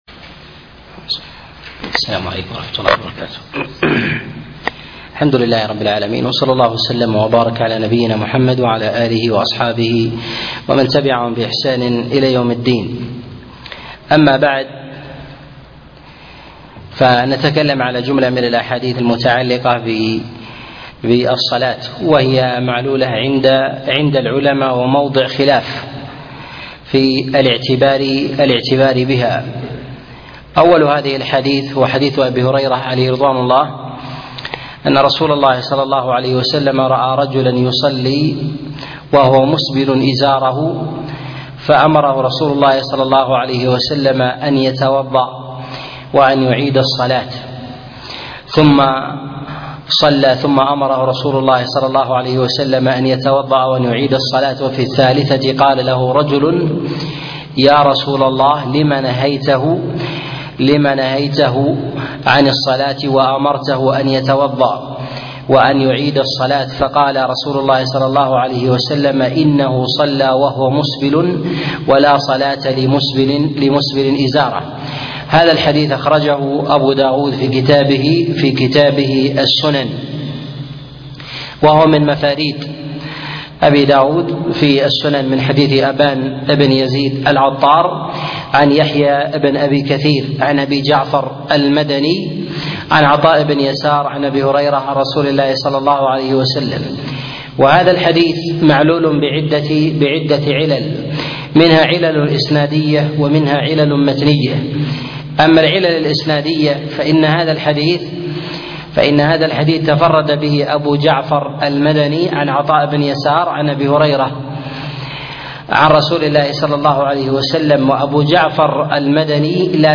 الأحاديث المعلة في الصلاة الدرس 6